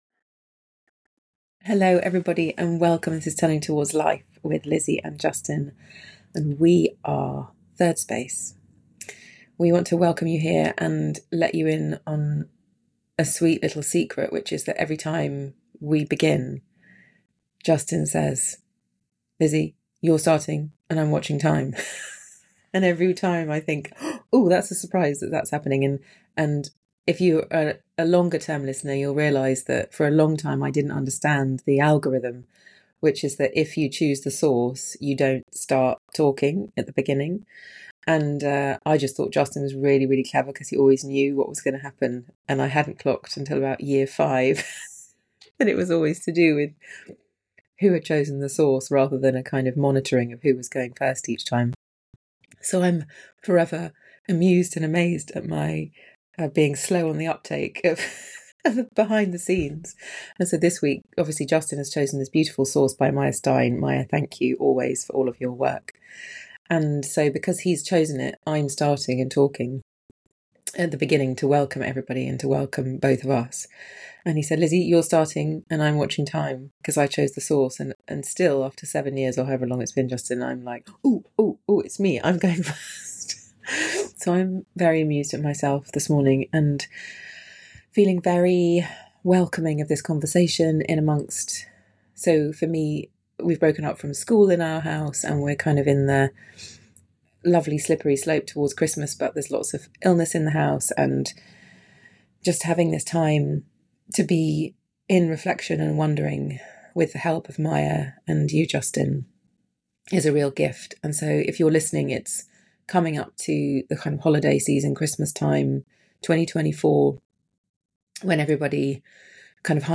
Find us on FaceBook to watch live and join in the lively conversation on this episode.